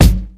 • Airy Kick Drum One Shot G Key 333.wav
Royality free kick single shot tuned to the G note. Loudest frequency: 833Hz
airy-kick-drum-one-shot-g-key-333-Gfv.wav